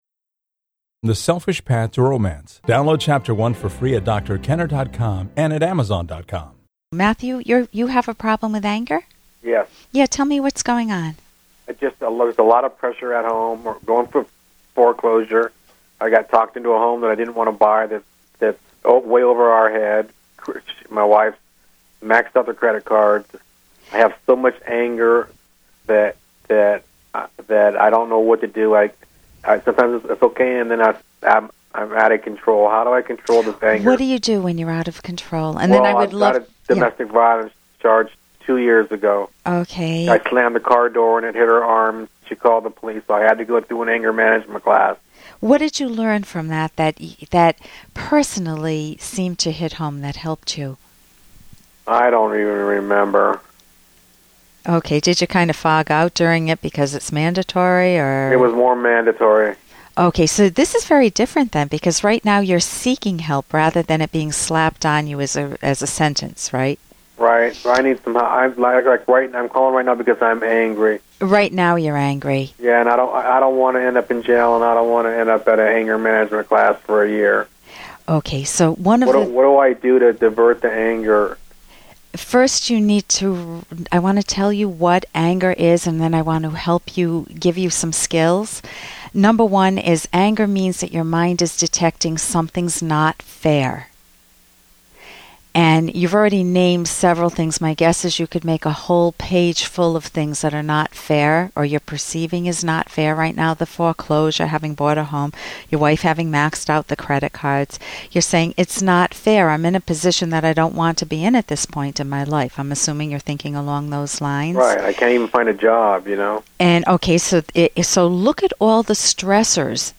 takes your calls and questions on parenting, romance, love, family, marriage, divorce, hobbies, career, mental health - any personal issue!